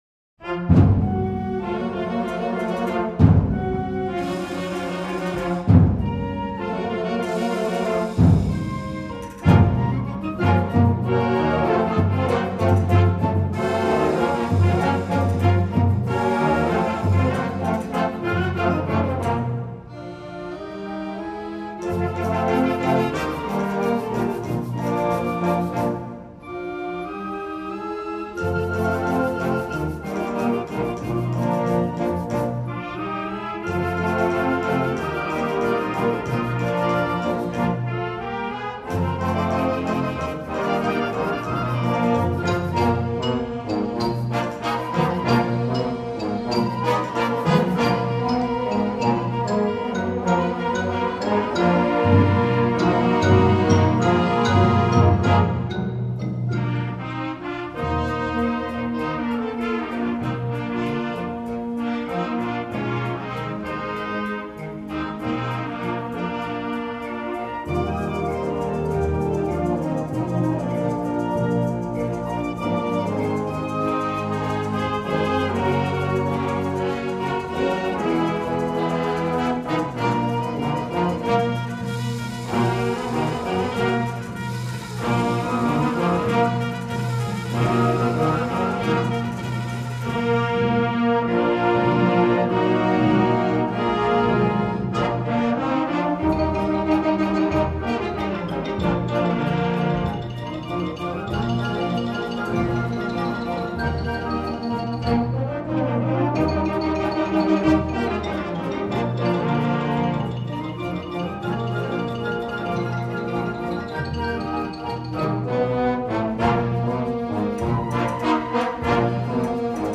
編成：吹奏楽
Tuba
Accordion
Maracas
Castanets
Marimba
Xylophone
Tenor Drum
Bass Drum